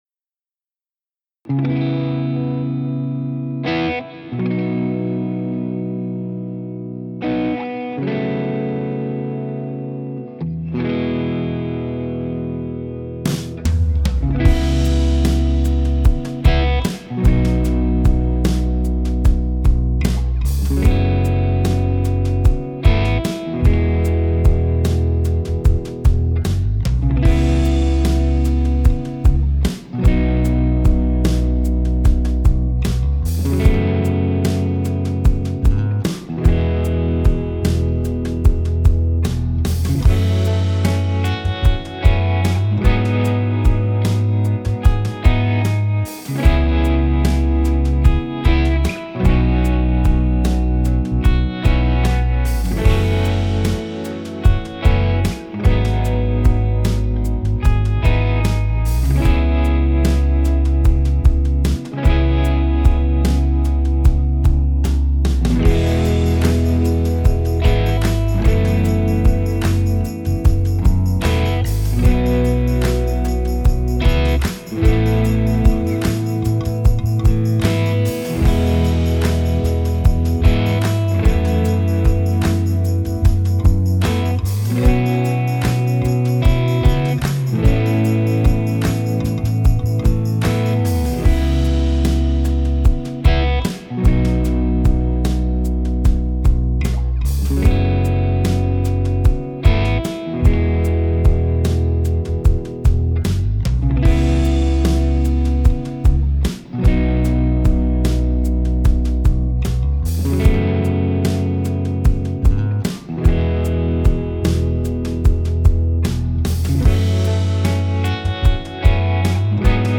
This pack features low to mid gain vintage tones.
No Post Processing.